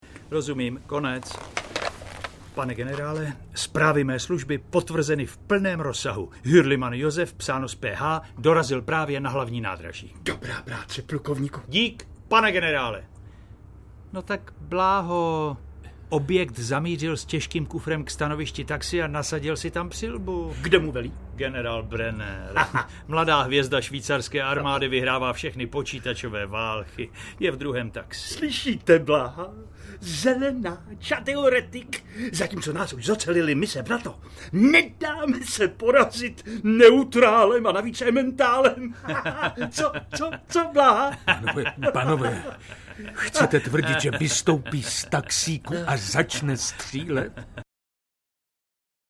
Válka ve třetím poschodí, Pech pod střechou, Požár v suterénu audiokniha
Smutní hrdinové příběhů, které najde v této rozhlascové hře, učiní právě s tímto druhem návštěvy nezapomenutelnou skušenost.
Ukázka z knihy
• InterpretBarbora Hrzánová, David Novotný, Miroslav Táborský, Viktor Preiss, Andrea Elsnerová, Daniela Kolářová, Miroslav Donutil